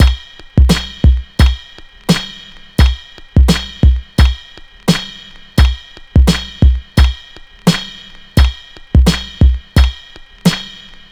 Free breakbeat sample - kick tuned to the C note. Loudest frequency: 772Hz
86-bpm-drum-beat-c-key-w9O.wav